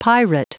Prononciation du mot pirate en anglais (fichier audio)
Prononciation du mot : pirate